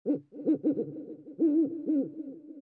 owl3.mp3